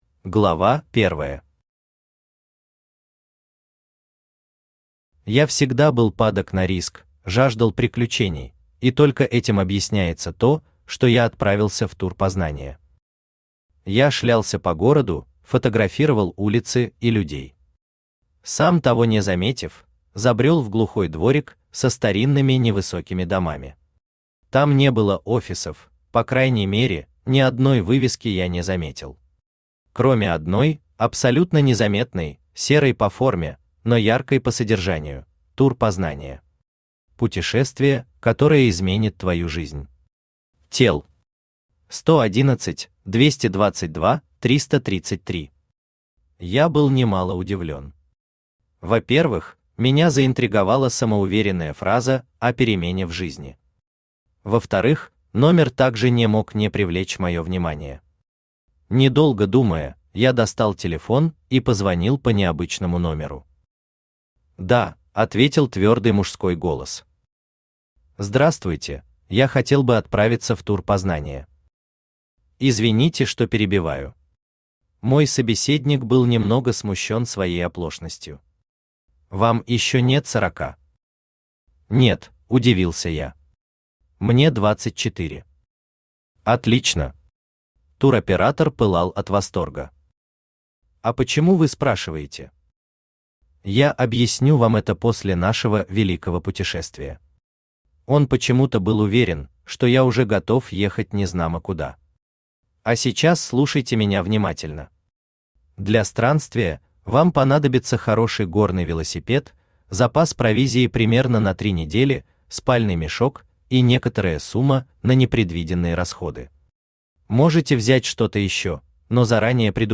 Aудиокнига Тур «Познание» Автор Александр Борисович Бодров Читает аудиокнигу Авточтец ЛитРес.